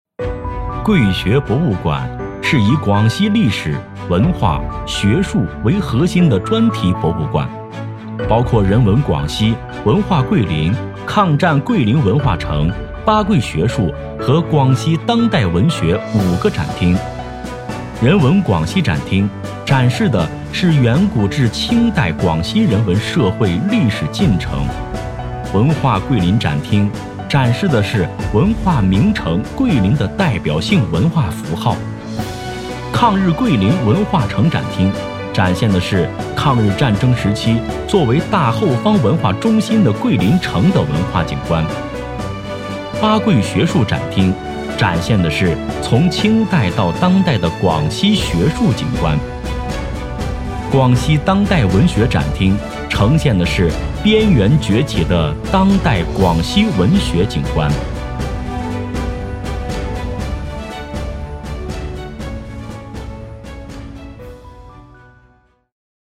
【男80号专题】桂学博物馆
【男80号专题】桂学博物馆.mp3